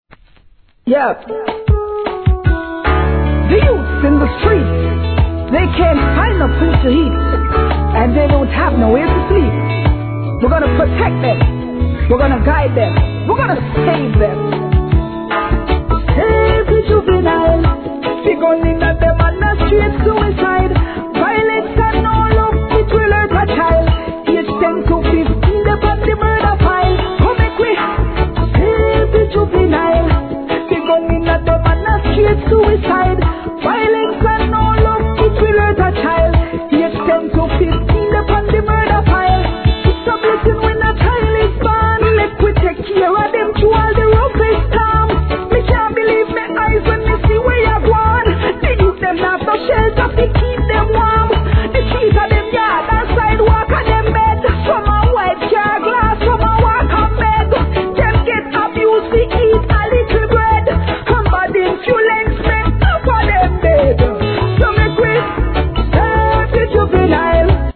REGGAE
味のあるSTYLEもイイ感じです!